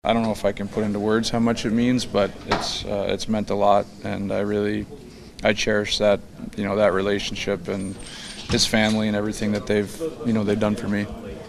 Crosby was humbled by the fact that Lemieux was there last night and recalled how Mario opened his home to him when he first came to the NHL.